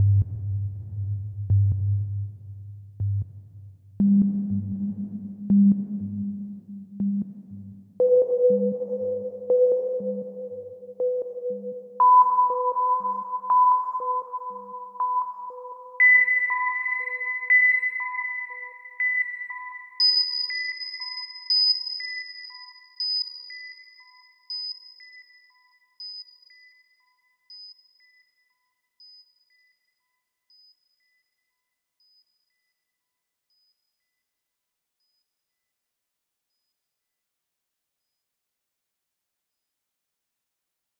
Atlas - STest1-PitchPulse-Right-100,200,500,1000,2000,5000.wav